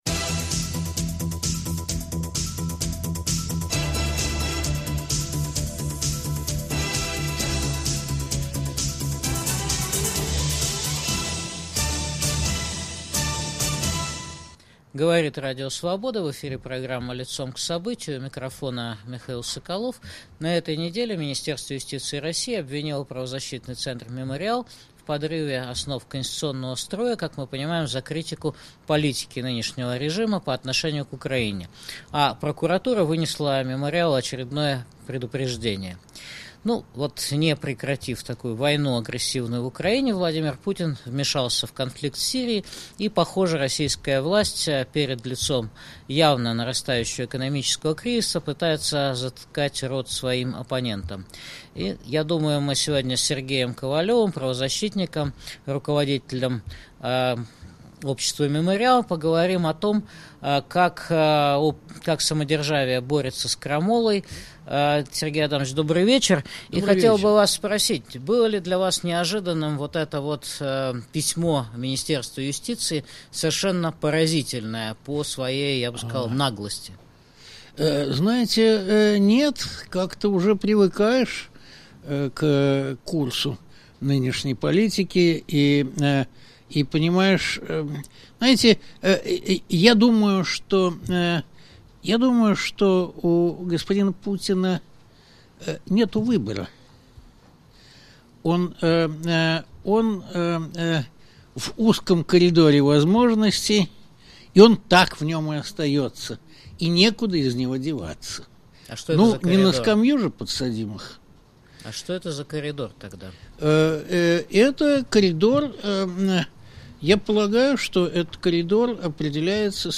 Беседуем с правозащитником, председателем правления Международного общества "Мемориал" Сергеем Ковалевым.